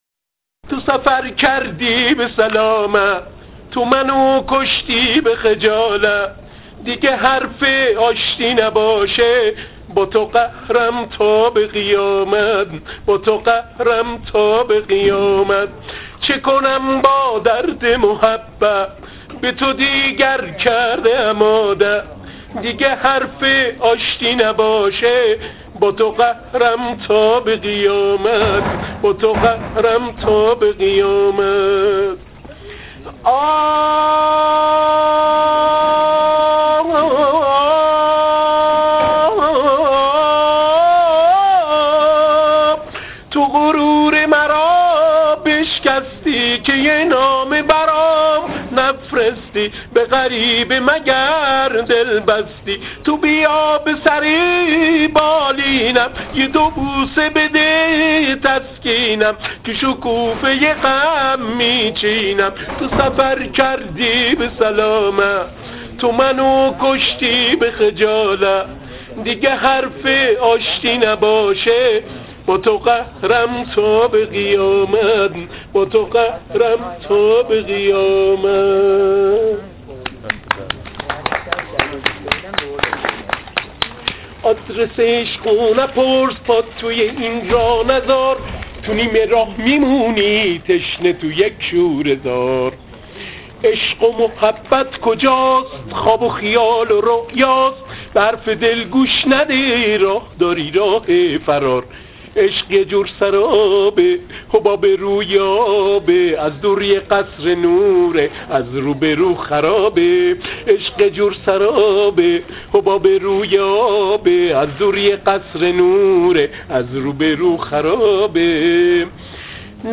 Enregistré un vendredi sur une coline de Shiraz:
iranian_song.mp3